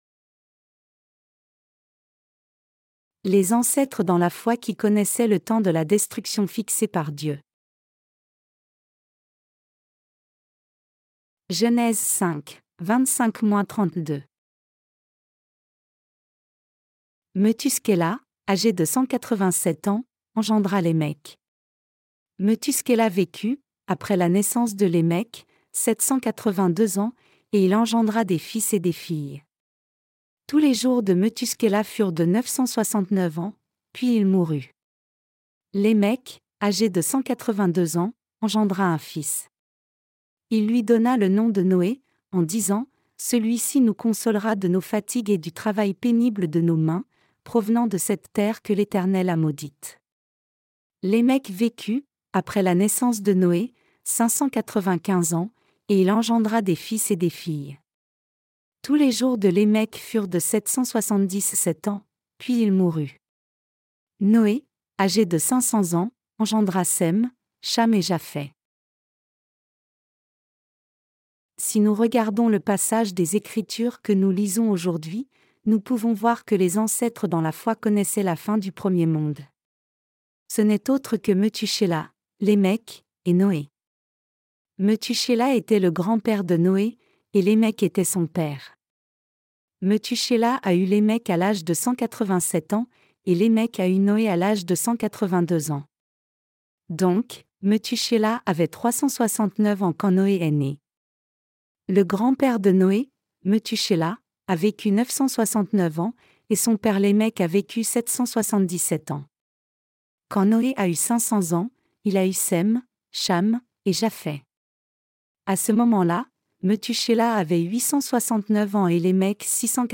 Sermons sur la Genèse (V) - LA DIFFERENCE ENTRE LA FOI D’ABEL ET LA FOI DE CAÏN 11.